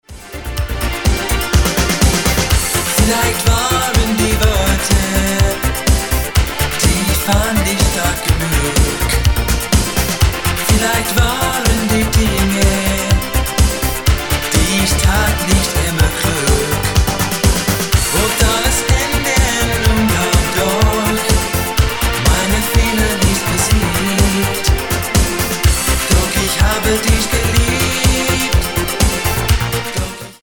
The Disco Star of the 80s!